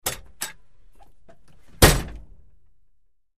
Door, Truck
Humvee Door Opens And Closes With Rattle